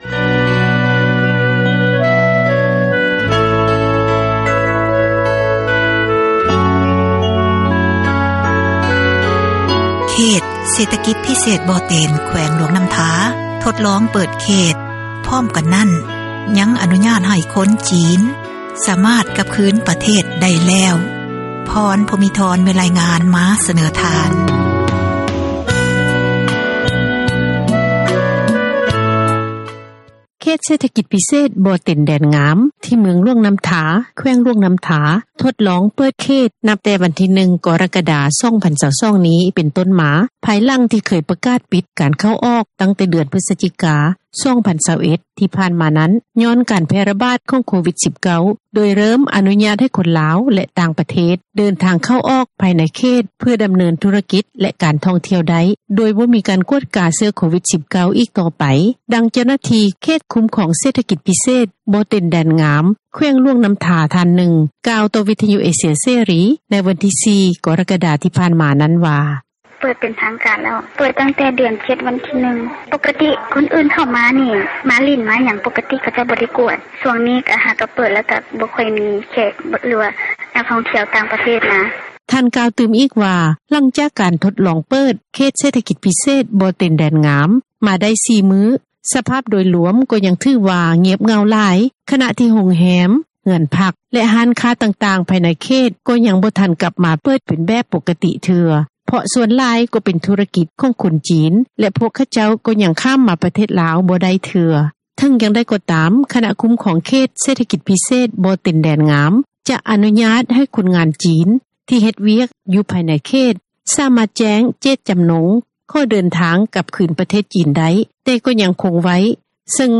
ດັ່ງເຈົ້າໜ້າທີ່ ຄຸ້ມຄອງເຂດເສຖກິຈພິເສດ ບໍ່ເຕັນແດນງາມ ແຂວງຫລວງນໍ້າທາ ທ່ານນຶ່ງ ກ່າວຕໍ່ ວິທະຍຸ ເອເຊັຽເສຣີ ໃນມື້ວັນທີ 4 ກໍຣະກະດາ ທີ່ຜ່ານມານີ້ວ່າ:
ດັ່ງເຈົ້າໜ້າທີ່ ຫ້ອງວ່າການ ແຂວງຫລວງນໍ້າທາທ່ານນຶ່ງ ກ່າວຕໍ່ວິທະຍຸ ເອເຊັຽເສຣີ ໃນມື້ດຽວກັນນັ້ນວ່າ: